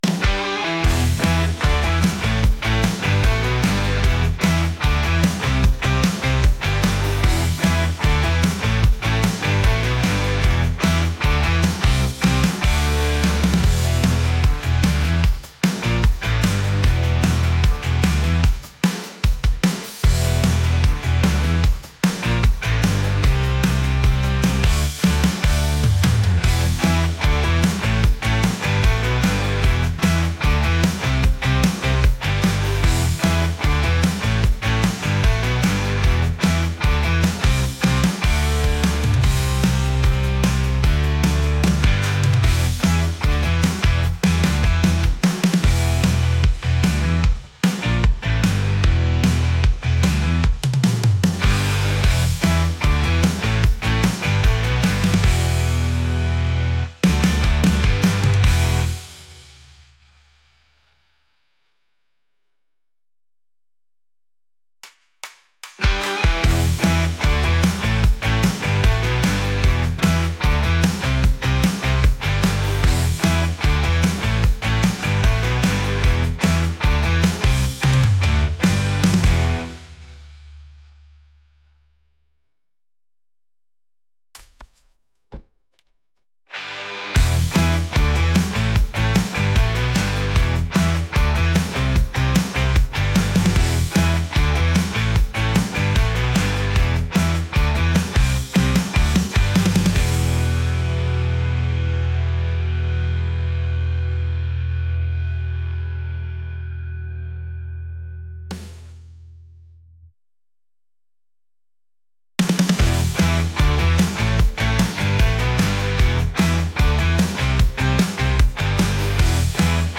rock | energetic